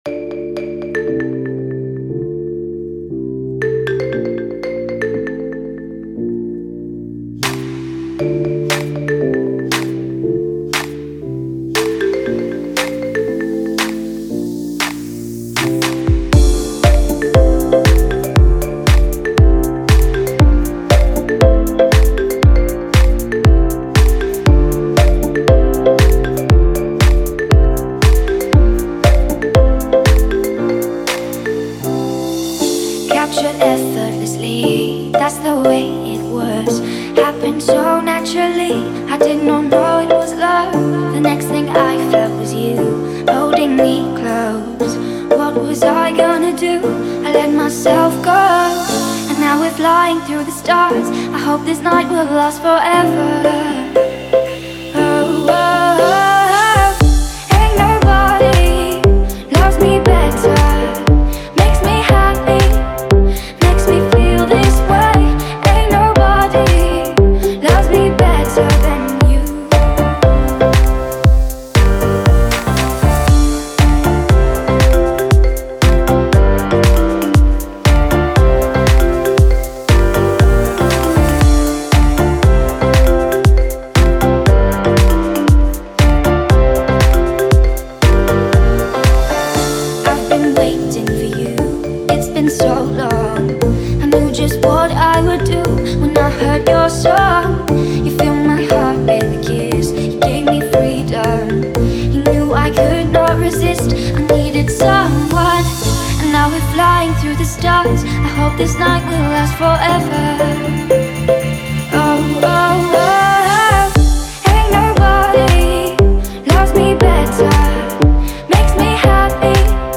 Назад в (pop)...